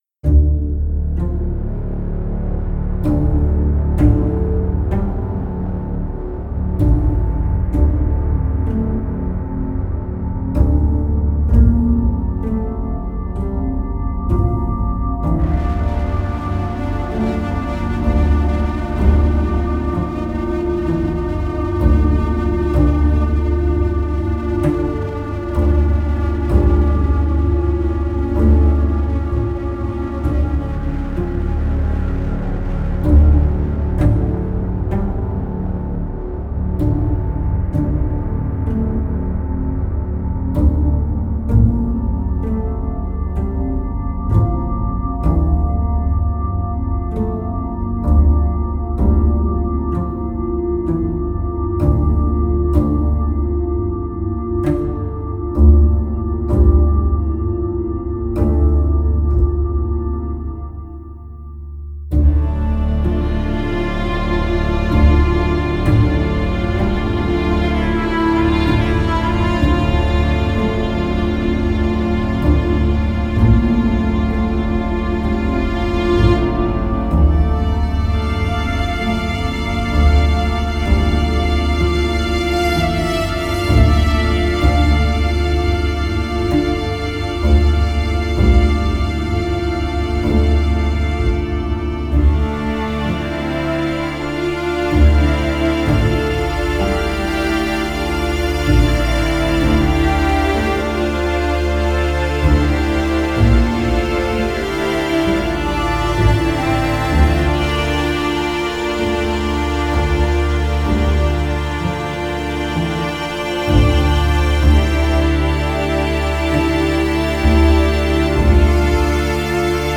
Hybrid & Action